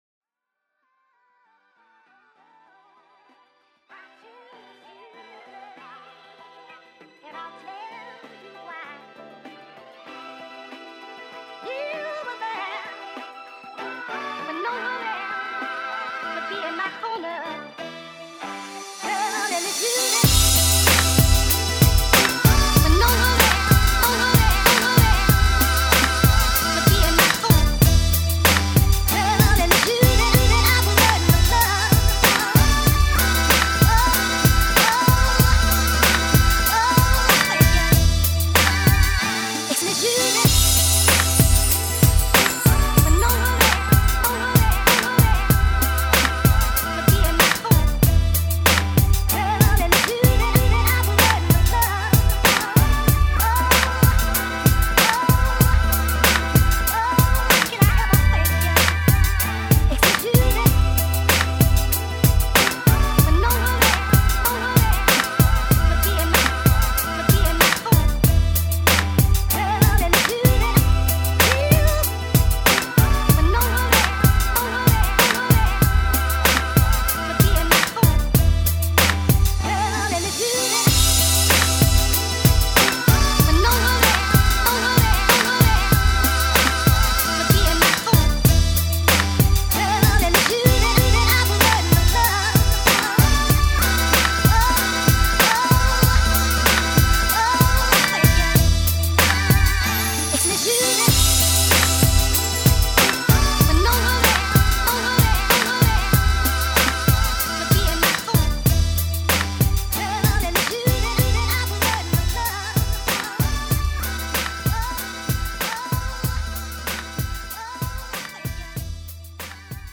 아쉬운 점만 말씀드리면요, 20초부분 킥이 조금 늦게 들어가는 게 아닌가 싶네요!!
베이스가 조금 약한게 아닌가 싶구요!